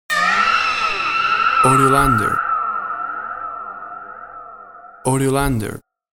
WAV Sample Rate 16-Bit Stereo, 44.1 kHz